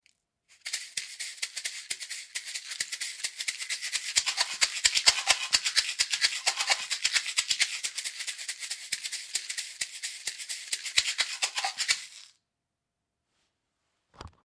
Inspiré du tambour d’aisselle africain, mais de petite taille,  ce hochet au nom étrange se joue en variant la tension des peaux avec les doigts et  offre  ainsi une riche palette de sonorités.